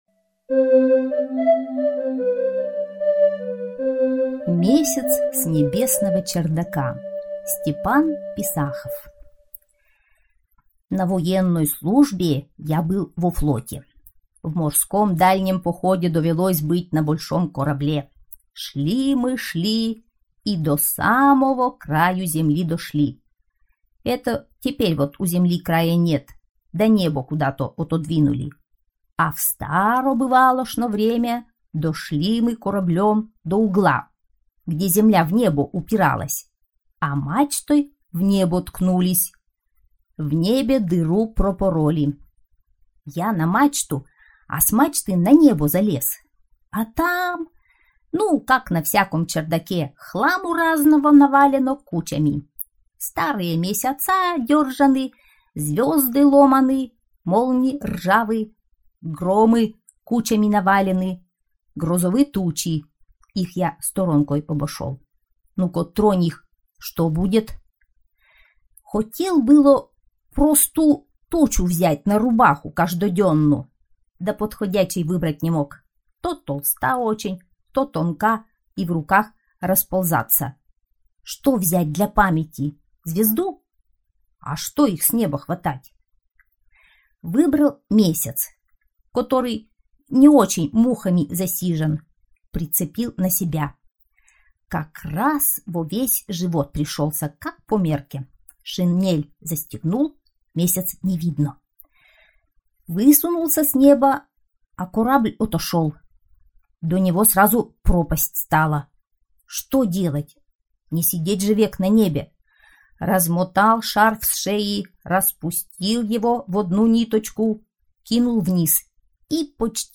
Месяц с небесного чердака - аудиосказка Писахова - слушать онлайн